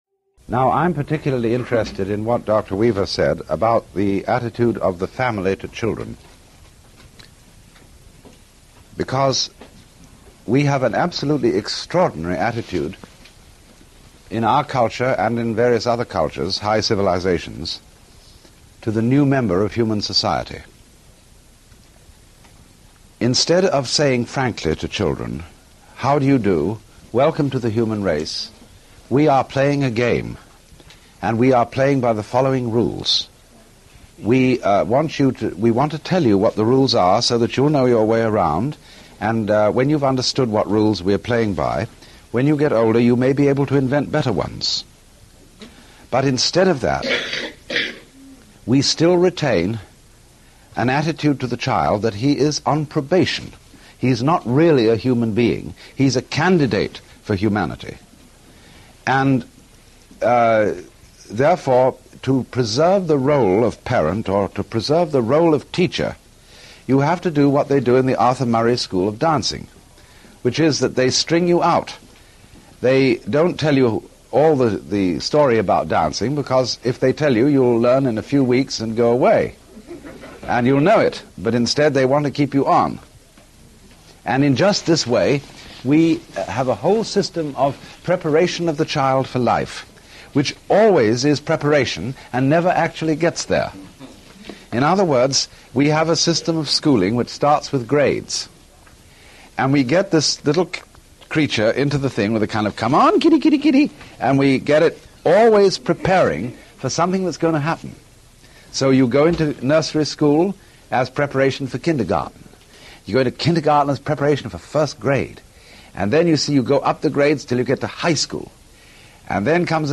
Alan Watts – Early Radio Talks – 14 – Man Is a Hoax